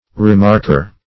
Remarker \Re*mark"er\ (-?r)